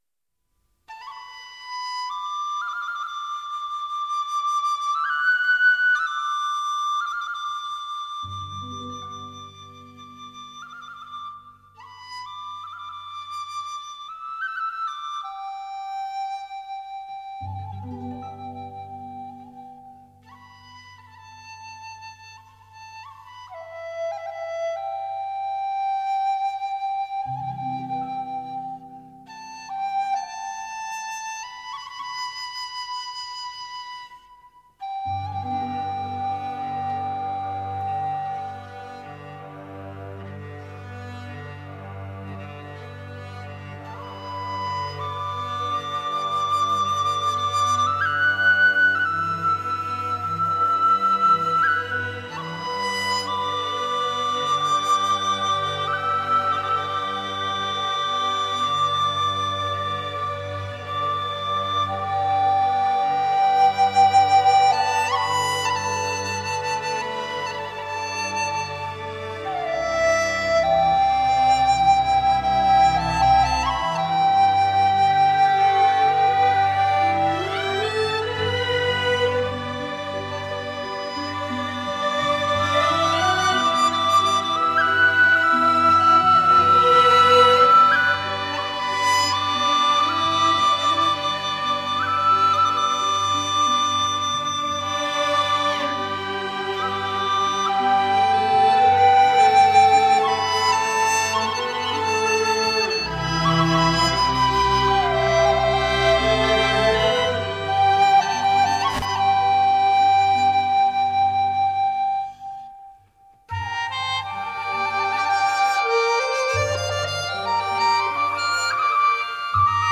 笛子